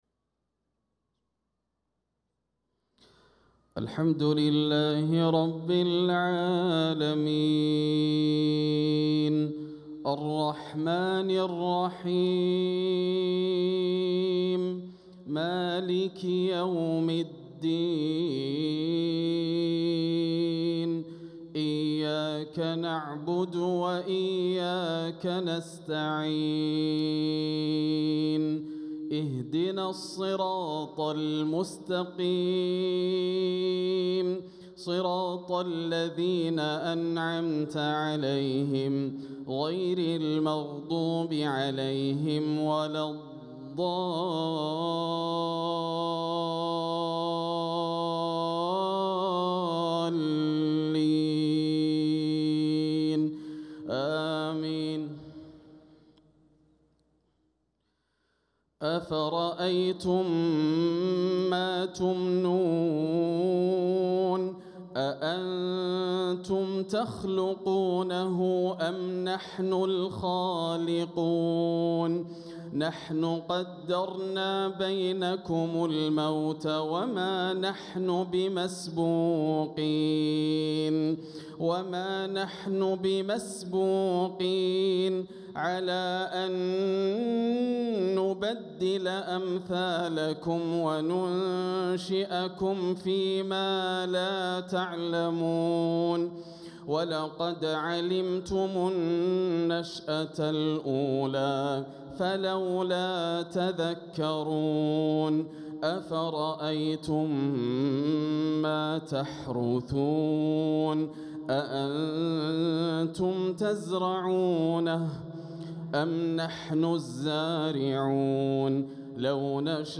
صلاة العشاء للقارئ ياسر الدوسري 2 ربيع الأول 1446 هـ
تِلَاوَات الْحَرَمَيْن .